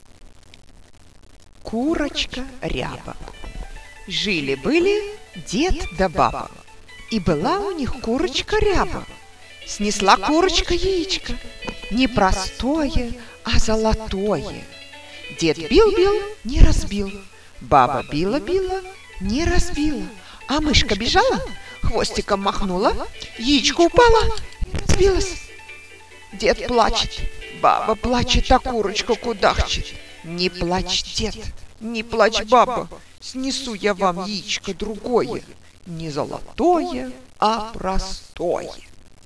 AudioLivreRusse (RussianAudioBook) personnalisé New (
Livre sonorisé, texte audio en russe à écouter : contes de fées, textes littéraires, blagues, proverbes, poèmes, etc...